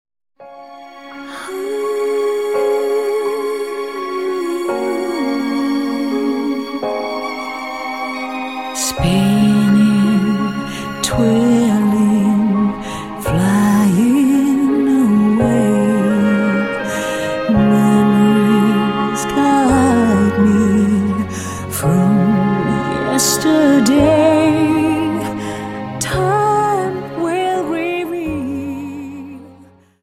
Dance: Slow Waltz 29